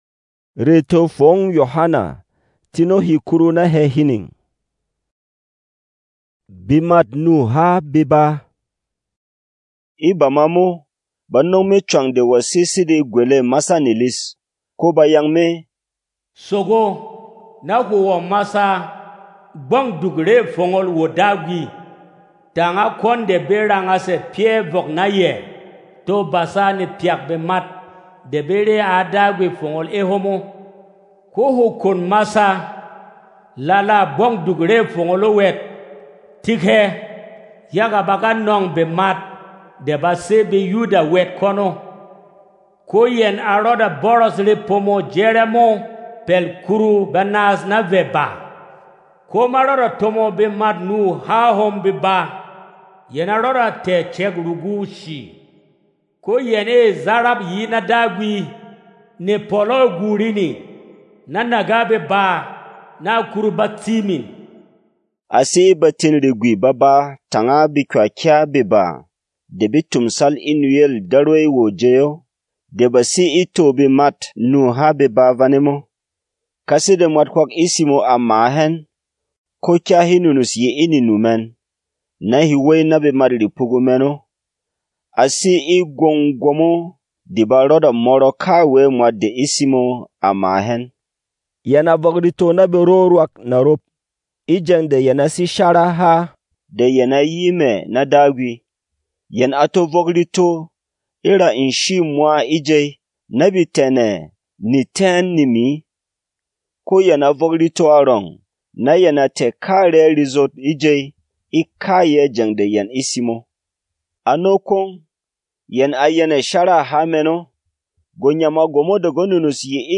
Non-Drama